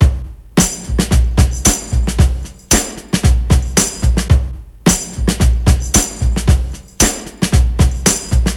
• 112 Bpm Breakbeat Sample C Key.wav
Free drum loop - kick tuned to the C note. Loudest frequency: 2340Hz
112-bpm-breakbeat-sample-c-key-QT4.wav